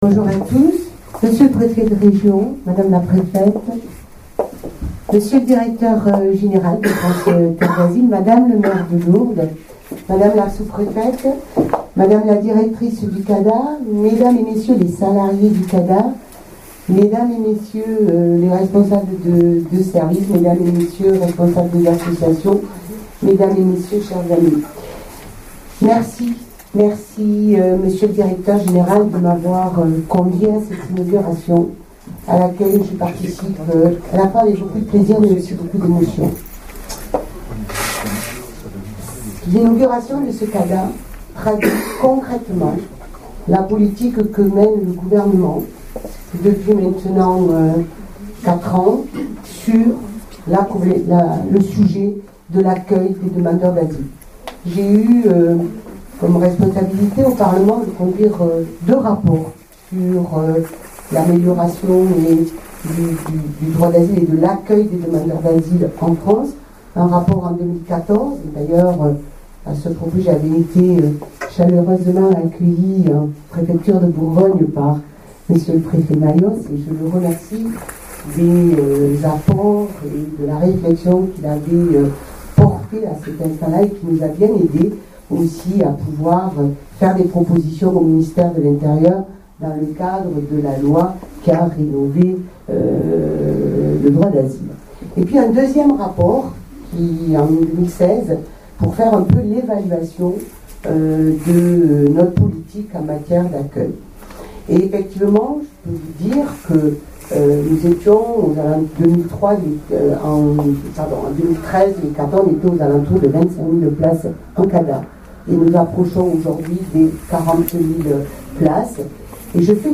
Sont disponibles en audio les discours des différents intervenants de l'inauguration:
Josette Bourdeu, Sous-préfète des Hautes-Pyrénnées